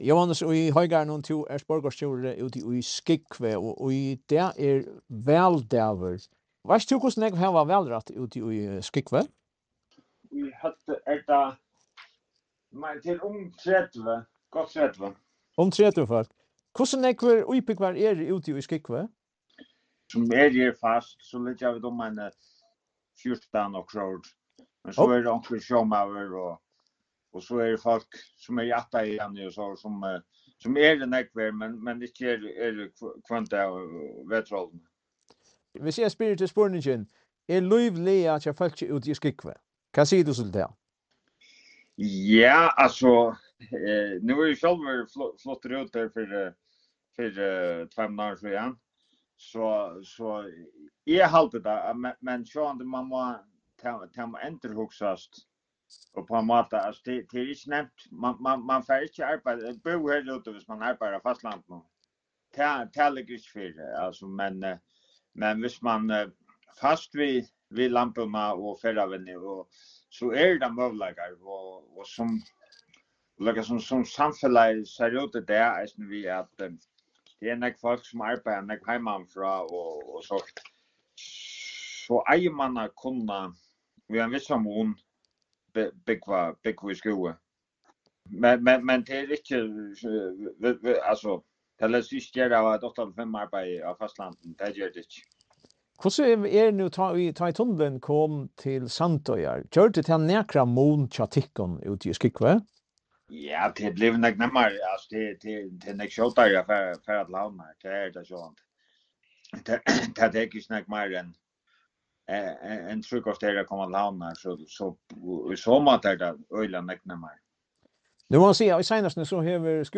Gestur í dagsins poddi er Jóannis í Hoygarðinum, borgarstjóri í Skúvoy.